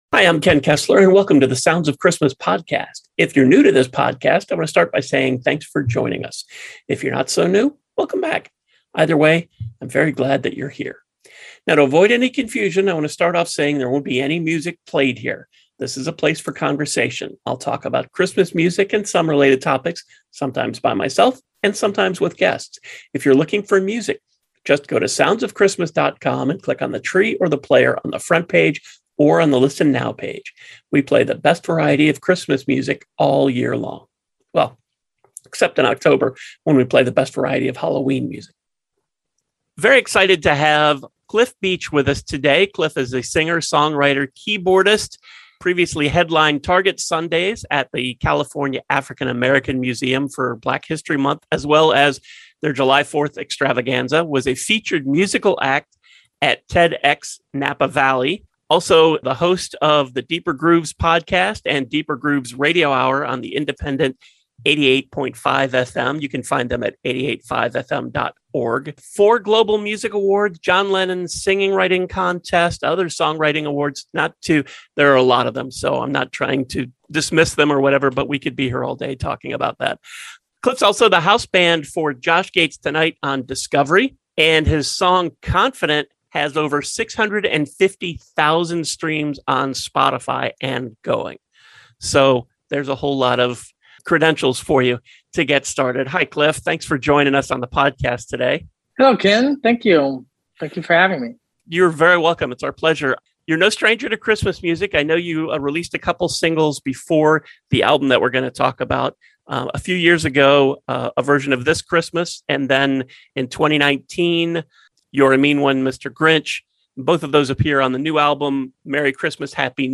Then it’s the quiz